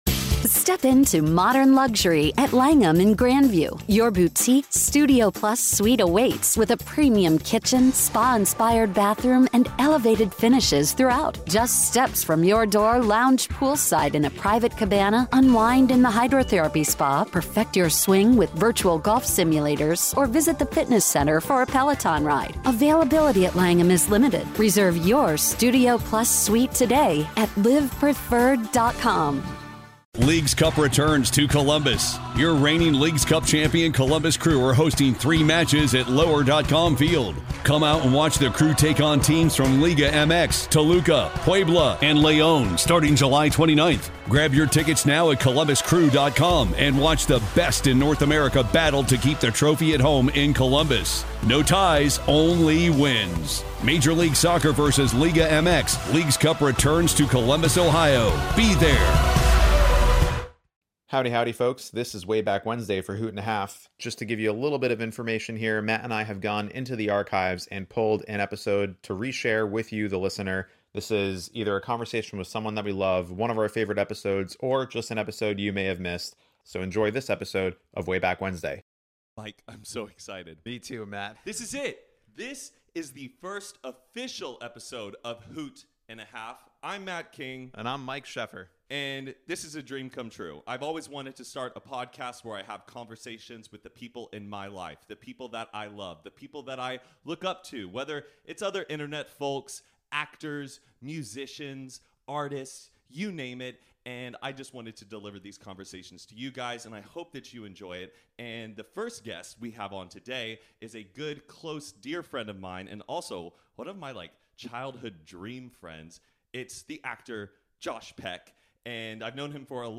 On our first WayBack Wednesday, we're taking it back to the VERY first Hoot & a Half. We interviewed our good friend Josh Peck, and wanted to re-share to relive the memories with y'all.